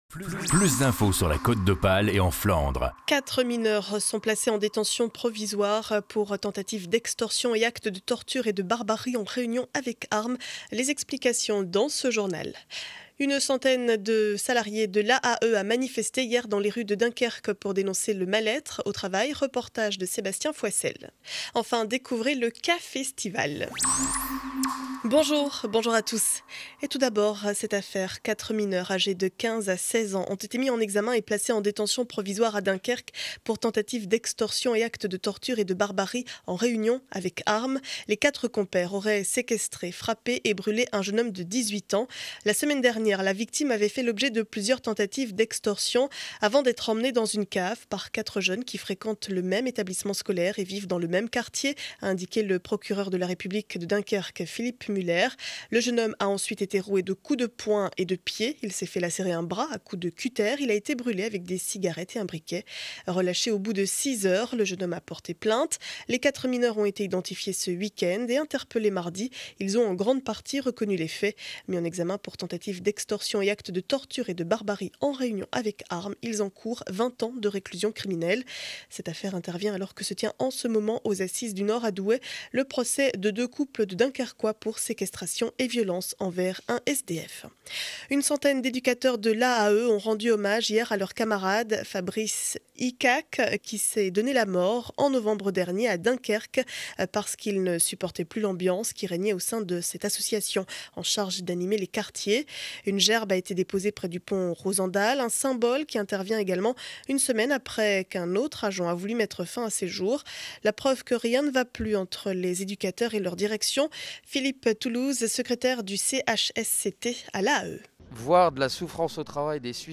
Journal du jeudi 05 avril 2012 7 heures 30 édition du Dunkerquois.